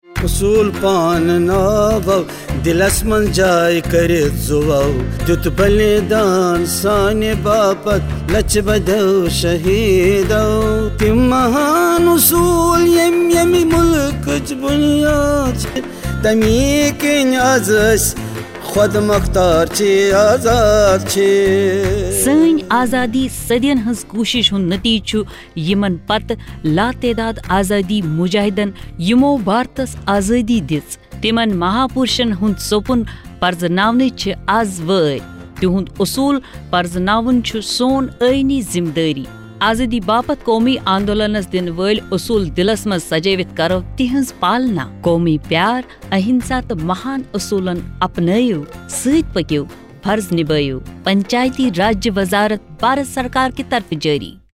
81 Fundamental Duty 2nd Fundamental Duty Follow ideals of the freedom struggle Radio Jingle Kashmiri